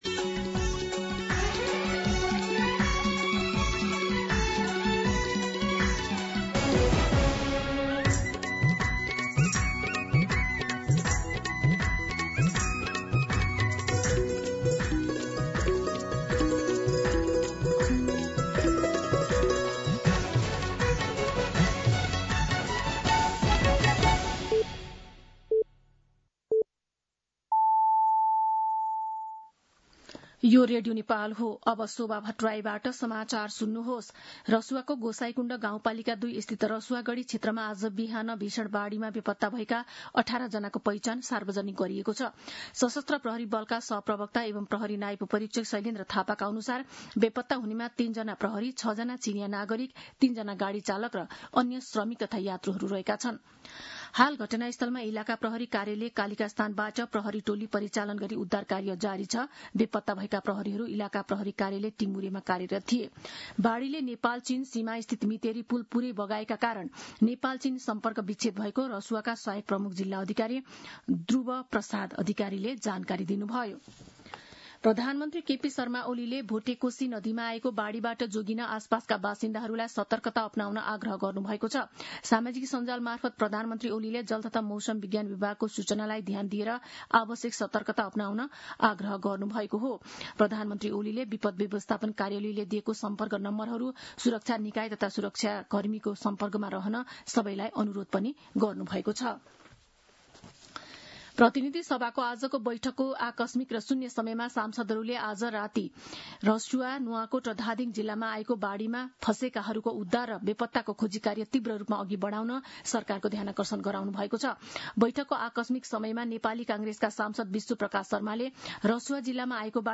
दिउँसो ४ बजेको नेपाली समाचार : २४ असार , २०८२
4pm-News-24.mp3